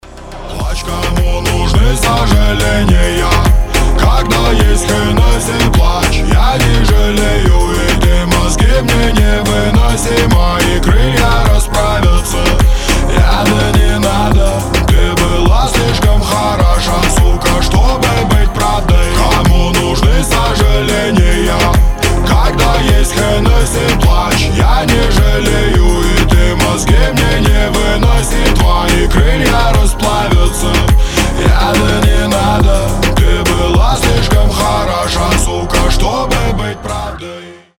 поп
мужской вокал